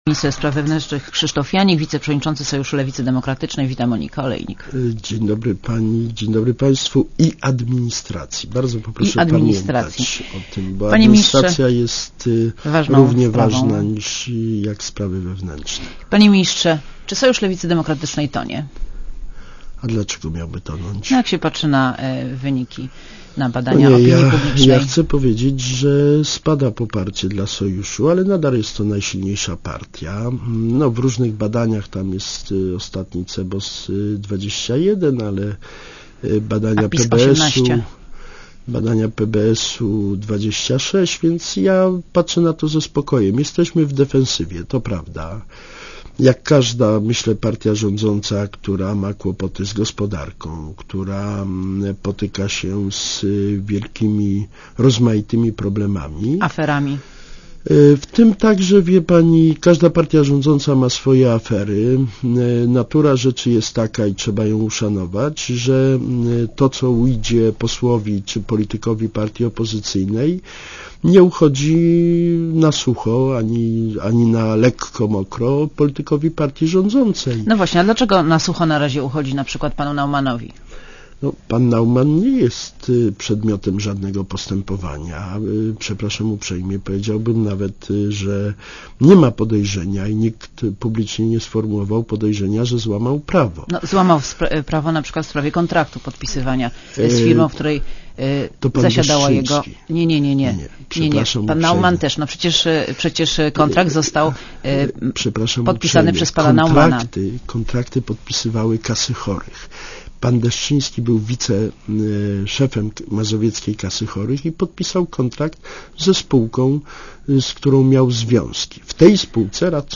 Krzysztof Janik w Radiu Zet (RadioZet)
(RadioZet) Źródło: (RadioZet) Posłuchaj wywiadu (2,6 MB) Krzysztof Janik, minister spraw wewnętrznych.